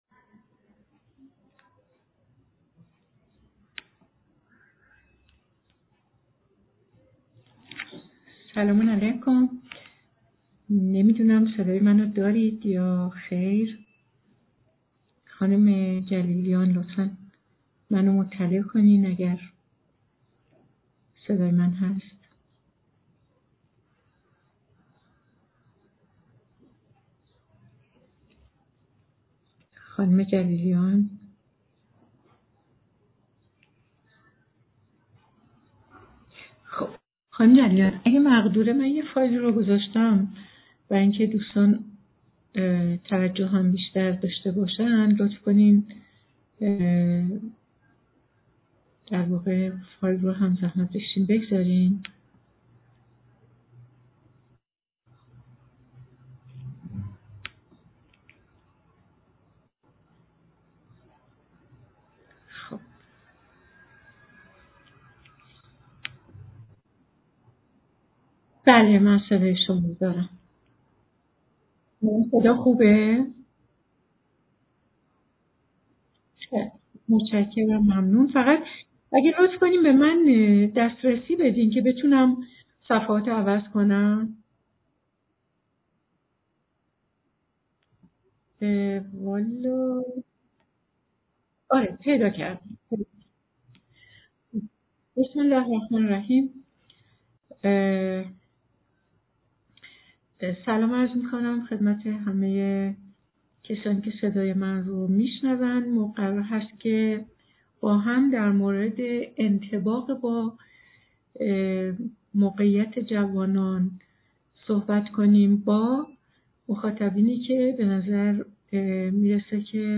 گفتنی است که سلسله ها کارگاه های آموزشی مختص والدین دانشجویان با شعار دانشگاه دوستدار خانواده بصورت هفتگی و با حضور اساتید مجرب روانشناس بصورت هفتگی و مجاری برگزار می شود.